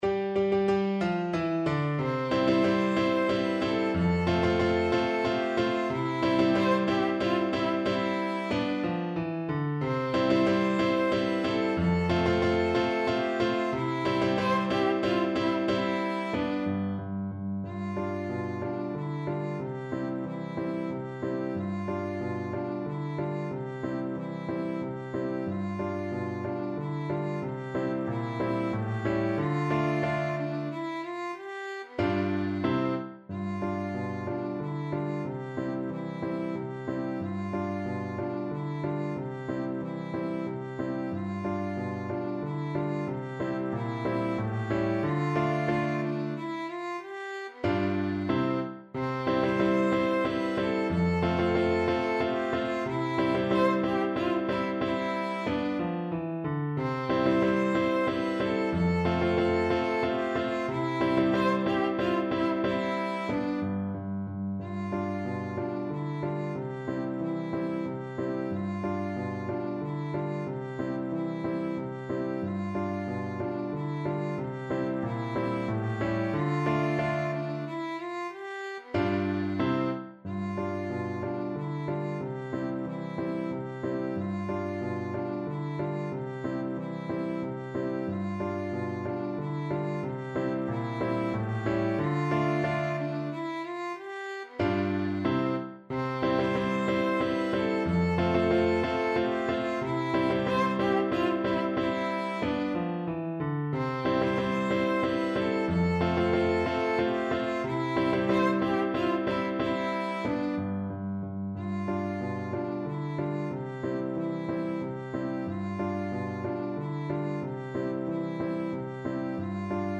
kolęda: Bóg się rodzi (na skrzypce i fortepian)
Symulacja akompaniamentu